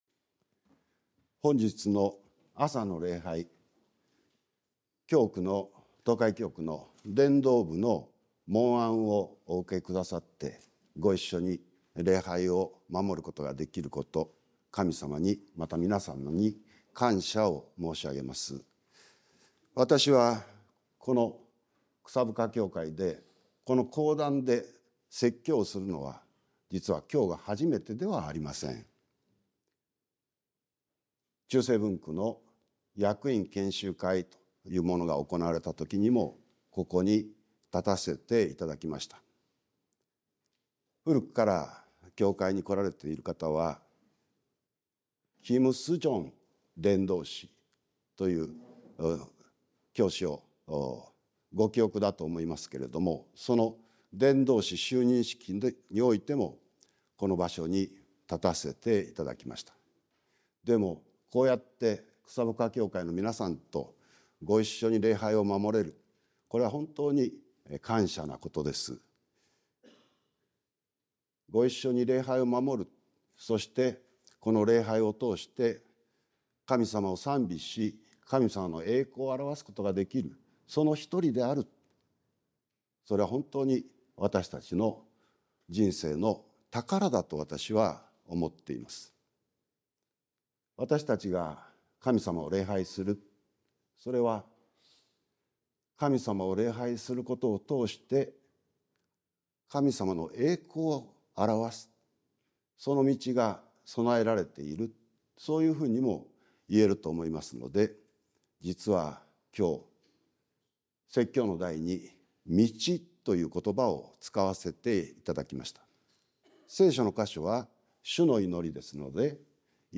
sermon-2024-11-03